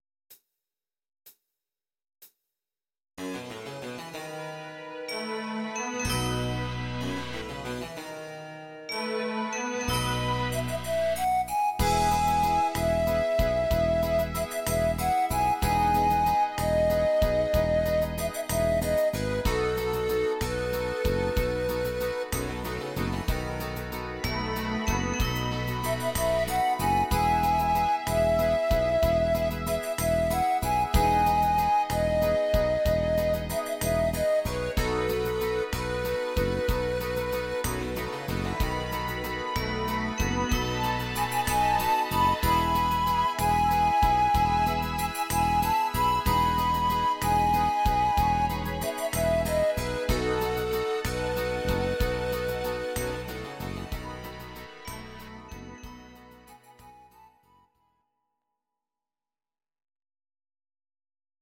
These are MP3 versions of our MIDI file catalogue.
Please note: no vocals and no karaoke included.
instr. Panflöte